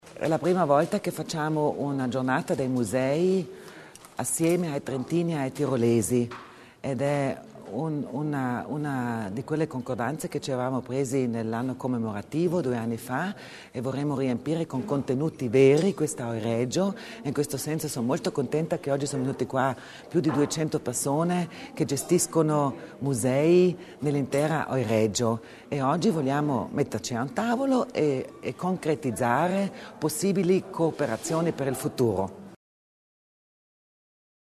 L’Assessore Kasslatter Mur sull’importanza della collaborazione in ambito museale
Oltre 150 operatori museali provenienti da Alto Adige, Trentino e Tirolo del Nord hanno preso parte questa mattina alla Giornata dei Musei del Tirolo storico, ospitata nell’Abbazia di Novacella presso Bressanone.